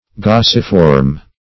Gasiform \Gas"i*form\